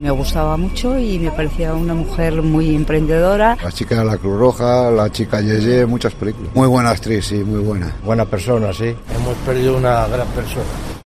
Los vallisoletanos despiden a Concha Velasco en los micrófonos de COPE Valladolid
En eso coinciden los vallisoletanos preguntados por COPE a las puertas del Teatro Calderón de Valladolid tras conocerse el fallecimiento de Concha Velasco a los 84 años de edad.